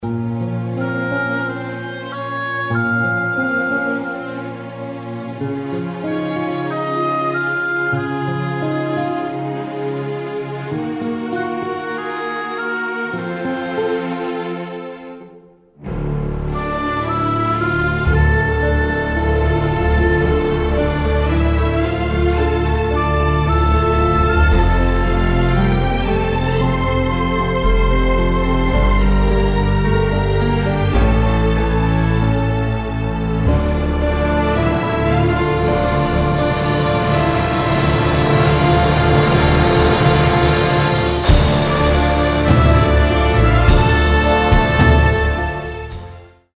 orchestral backing track
key: Bb
Instrumental